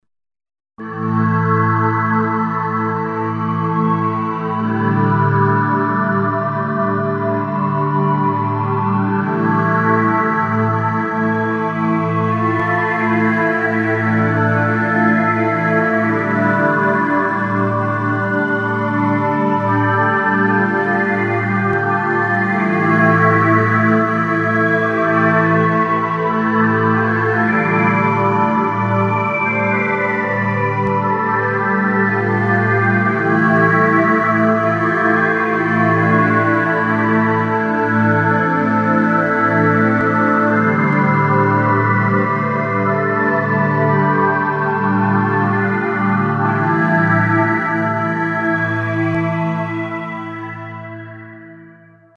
Z-plane Synthesizer (1993)
another deep pad